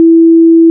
signalgreen.wav